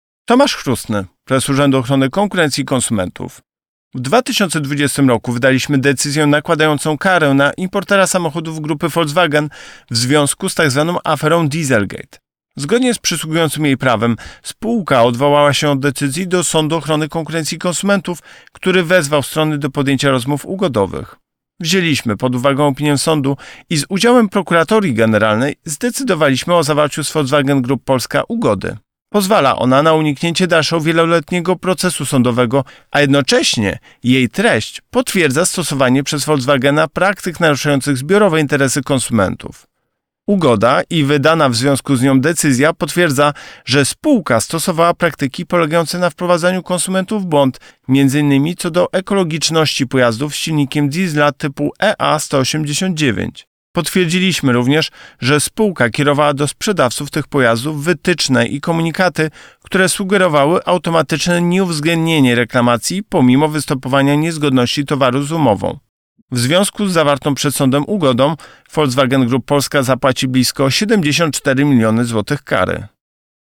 Wypowiedź Prezesa UOKiK Tomasza Chróstnego